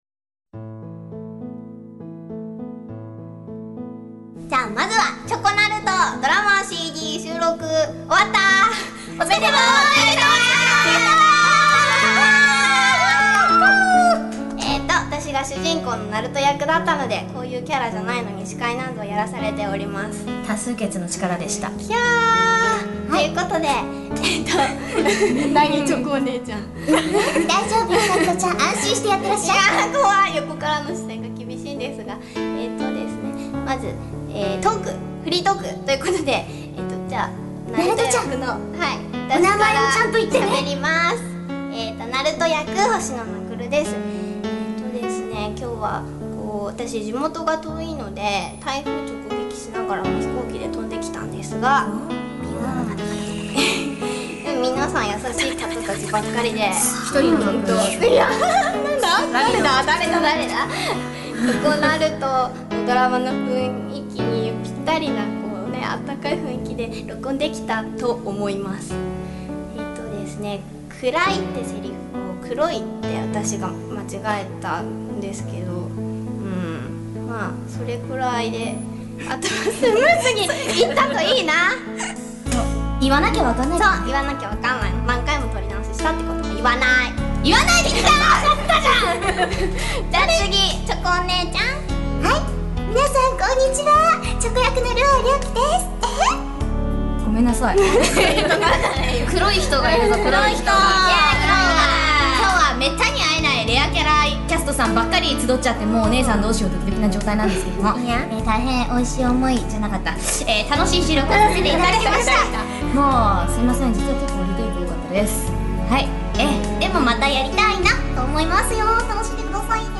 キャストフリートーク（3分50秒　配信：03/12/01）